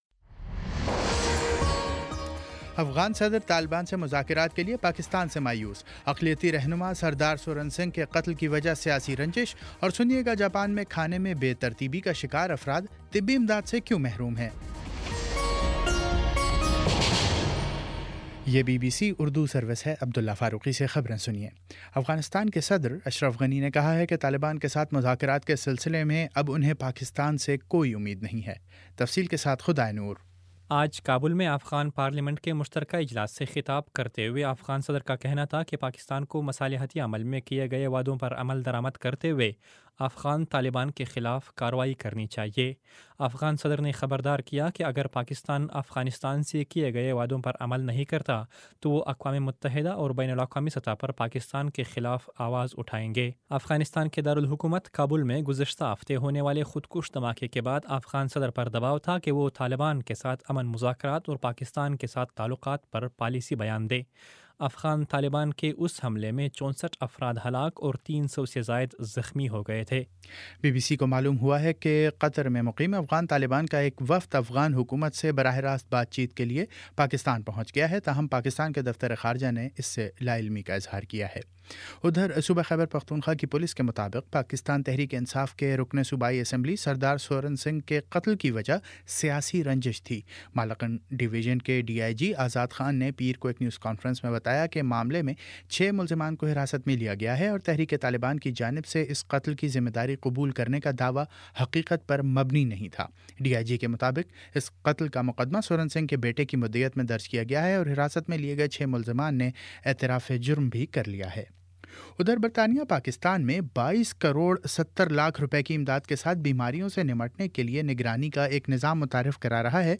اپریل 25 : شام پانچ بجے کا نیوز بُلیٹن